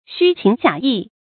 xū qíng jiǎ yì
虚情假意发音
成语正音 假，不能读作“jià”。